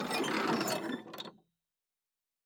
pgs/Assets/Audio/Sci-Fi Sounds/Mechanical/Engine 2 Stop.wav at 7452e70b8c5ad2f7daae623e1a952eb18c9caab4
Engine 2 Stop.wav